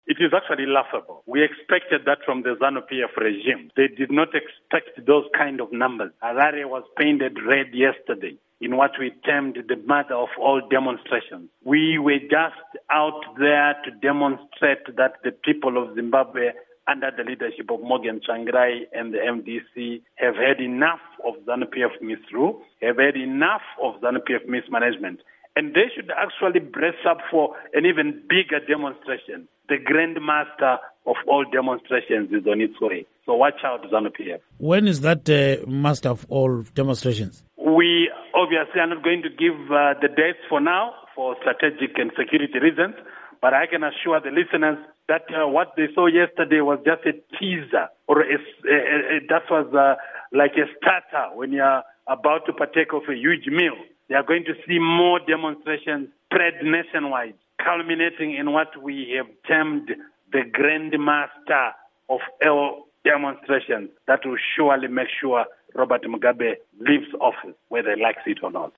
Interview With Obert Gutu on Public Protests